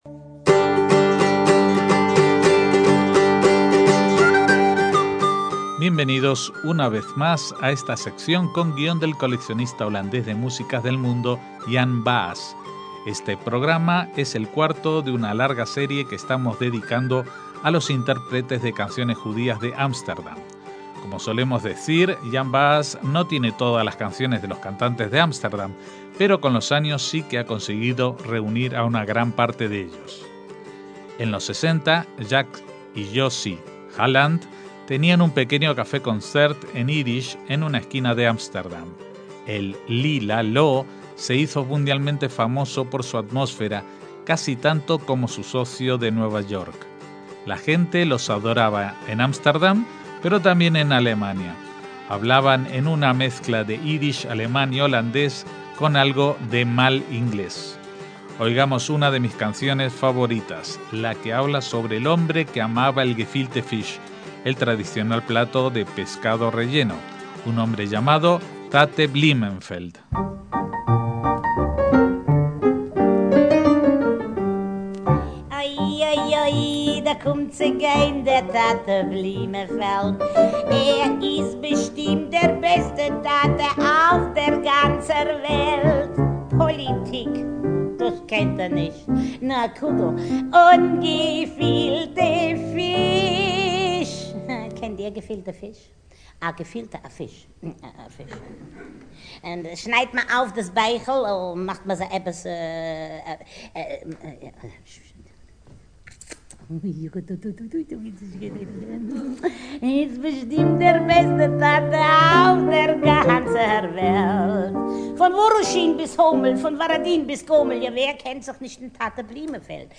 Esta es la cuarta de las entregas que dedicaremos a cantantes judíos de Ámsterdam a través de los tiempos, y se centra en el género del café concert.